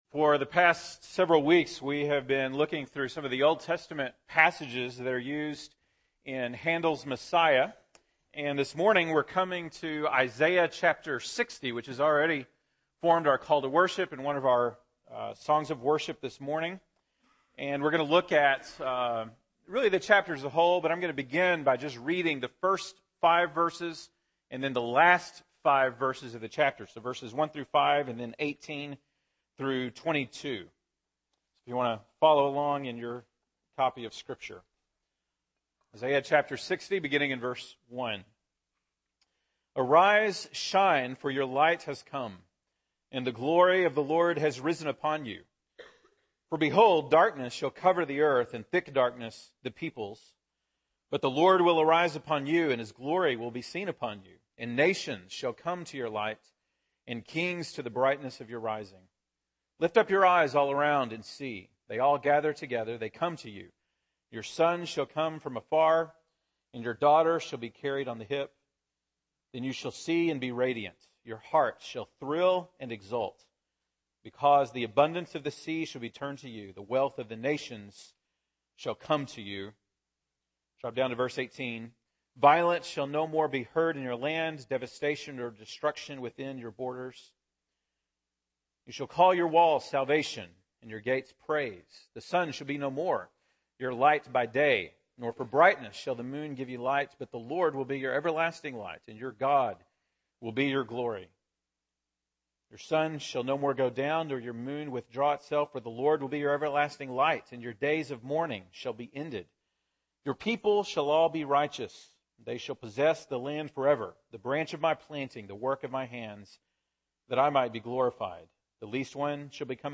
December 14, 2014 (Sunday Morning)